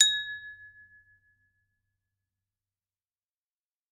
Одиночный звон удара ложкой о стакан дзынь